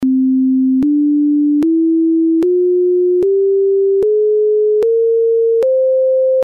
Solution 1 : une division arithmétique entre les cordes de longueur 1 et 1/2 ; la raison de la suite est 1/14.